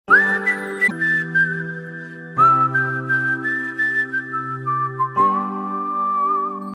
Samsung Sms RingTones